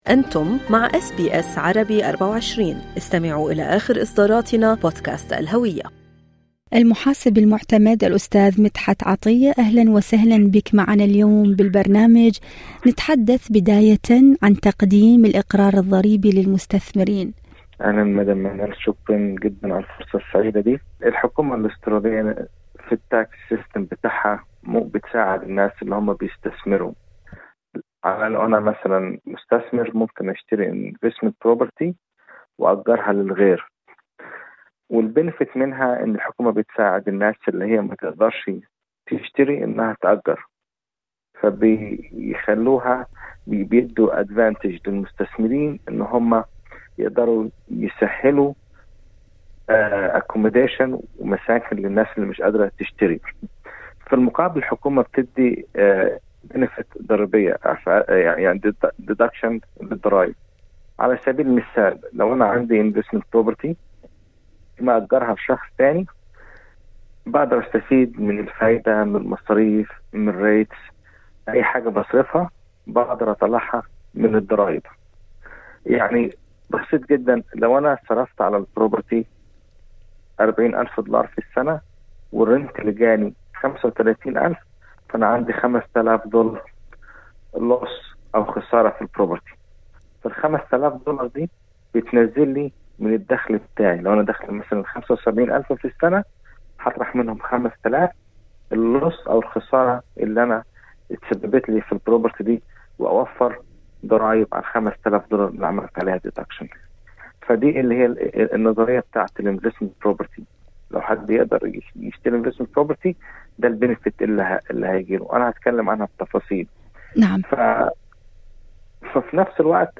المقابلة الصوتية